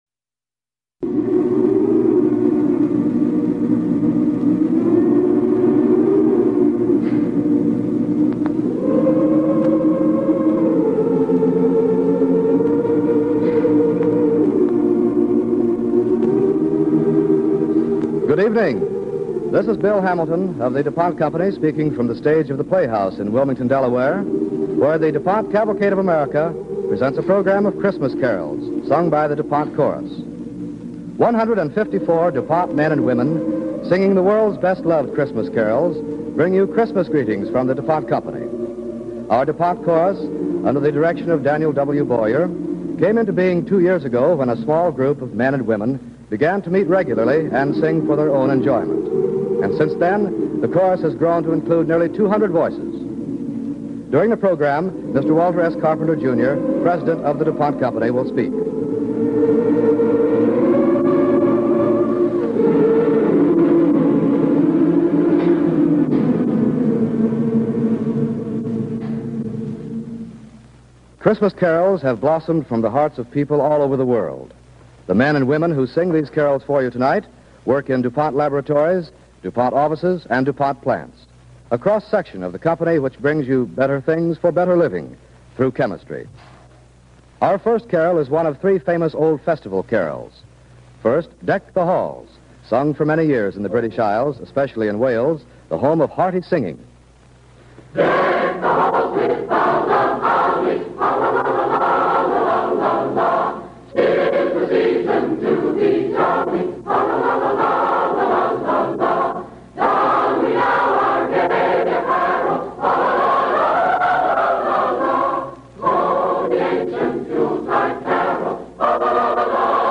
The DuPont Chorus Sings Christmas Carols